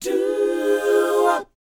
DOWOP A#4G.wav